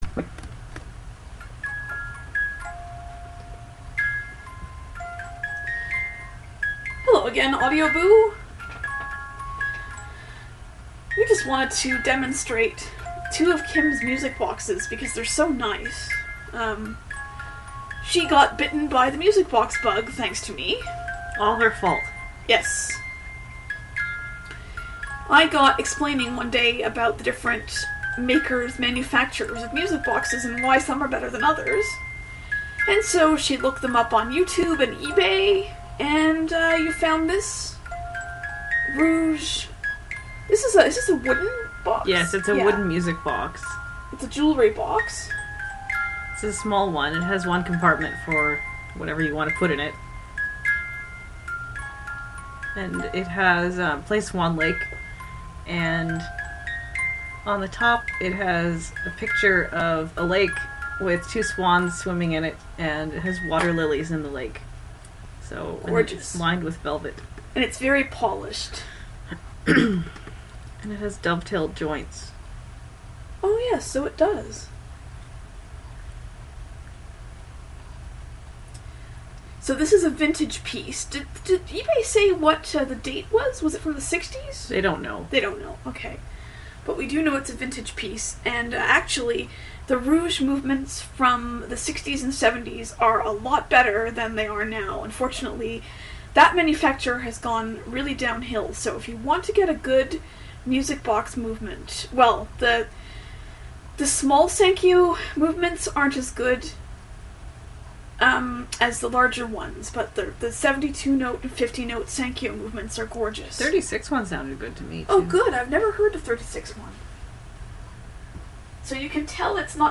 a Demonstration of Two Beautiful Vintage Music Boxes